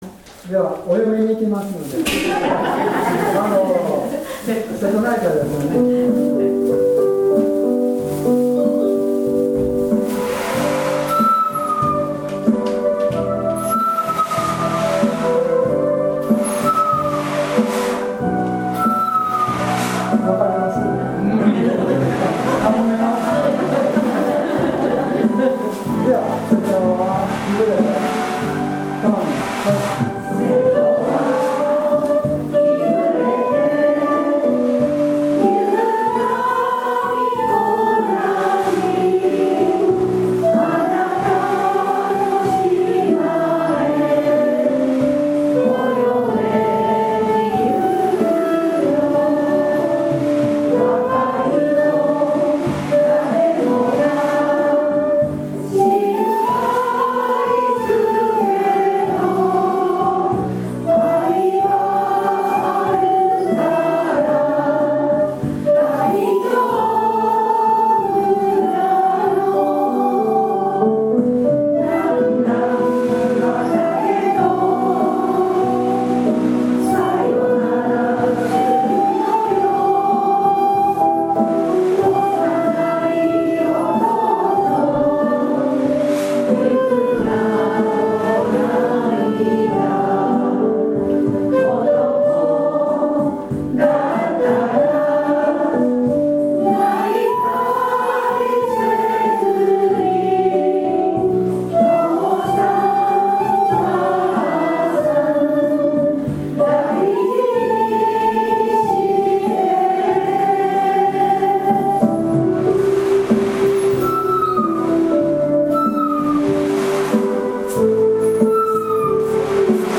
ウエーブドラムといって波の音のする楽器を補助に入れてもらい、前奏では尺八で鳥の声を真似て吹いています。